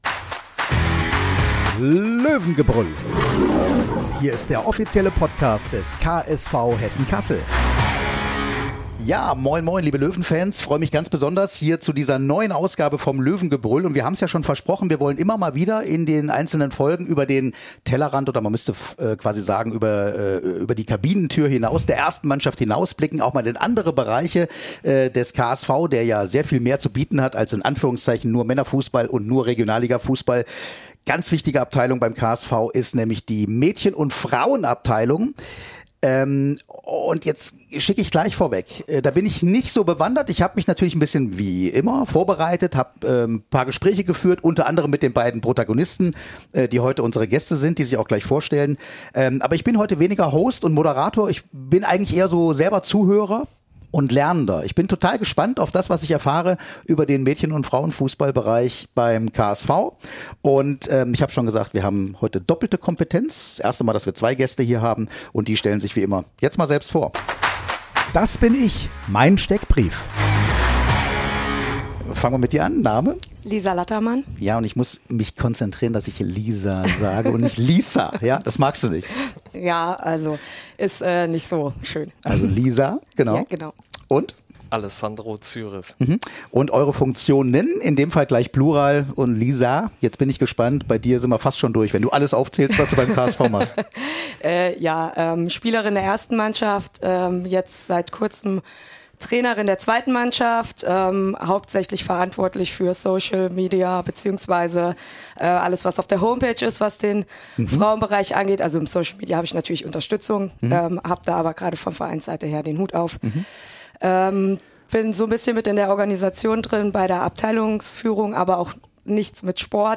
Auch Spieler:innen von Frauen- und Männer-Teams kommen zu Wort.